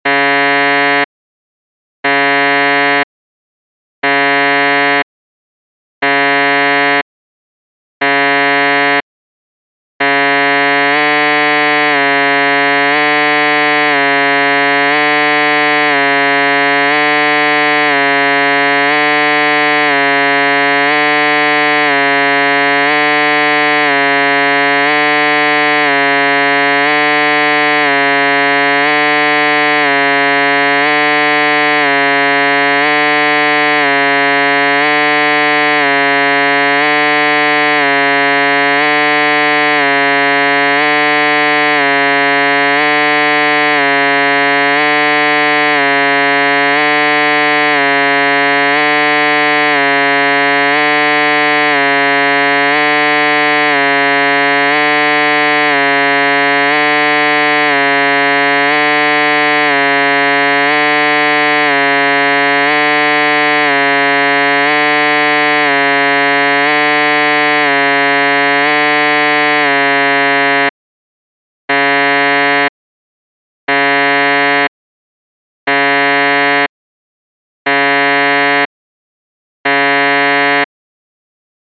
UVB-76 - The Buzzer On 4625kHz
odd-sounds-on-shortwave-the-buzzer.mp3